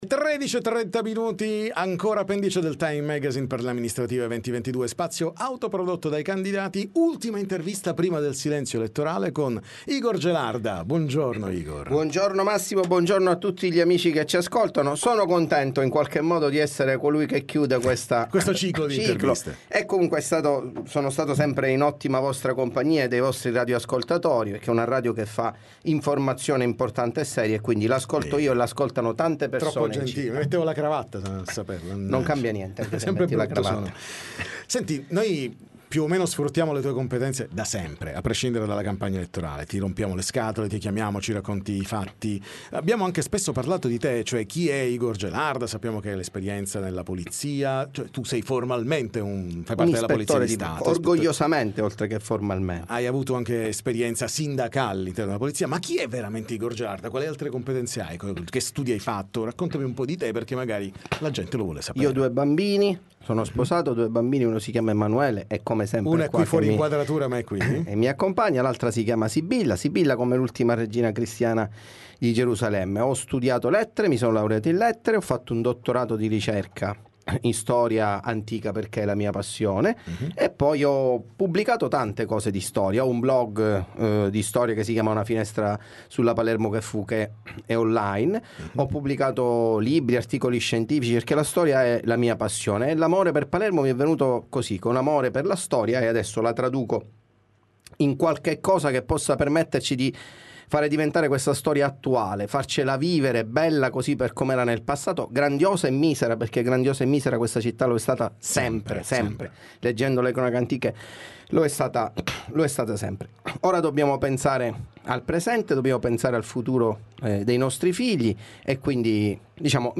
TM intervista Igor Gelarda